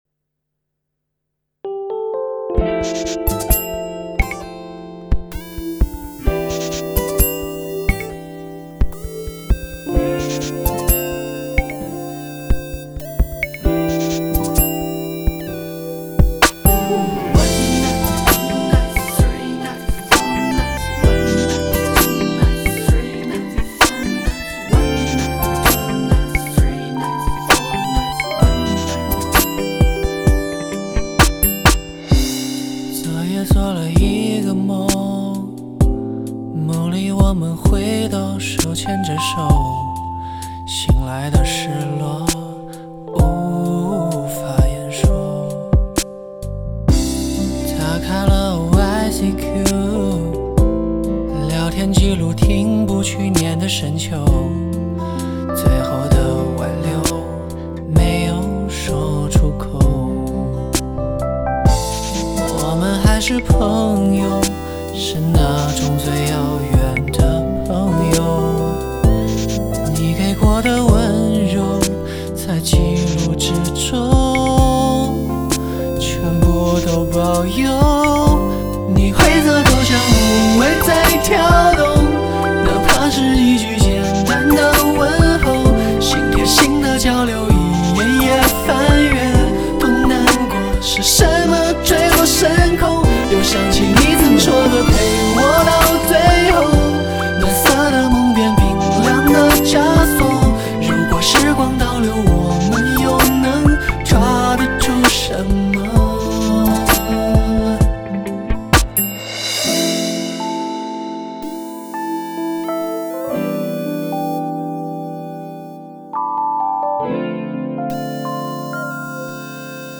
电音 收藏 下载